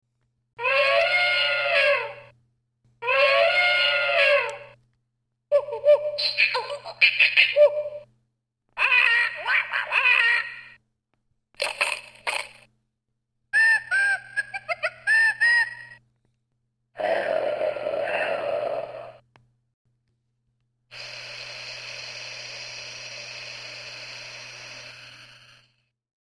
Download Free Various Animals Sound Effects
Various Animals